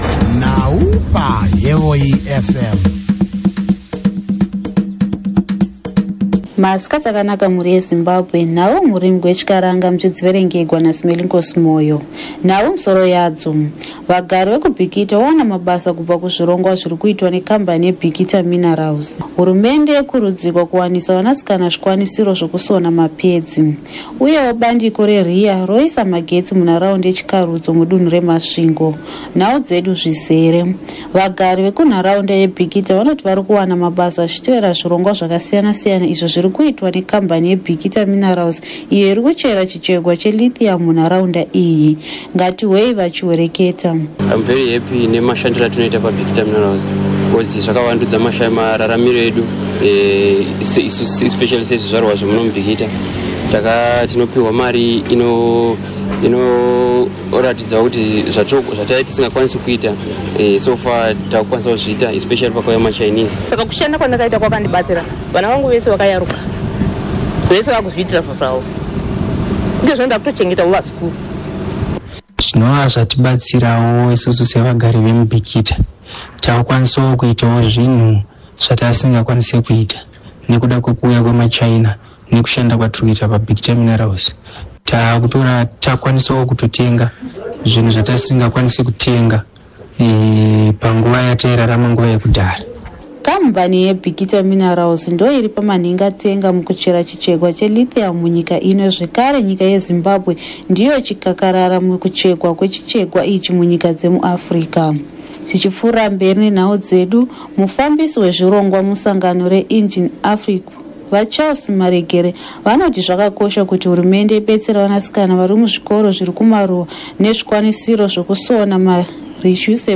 This podcast Hevoi FM news bulletin zeroes in on some of the marginal employment opportunities created by Bikita Minerals and accessible by ordinary community members. Though many people have been fortunate enough to gain entry into the formal job market, skilled opportunities remain largely out of reach for locals.